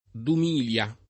dum&lLa]: un mille o un dumilia fiorini [um m&lle o un dum&lLa fLor&ni] (Boccaccio) — spesso con l’iniz. maiusc. il D. (o il d.), l’anno 2000 oppure il secolo XXI — cfr. mila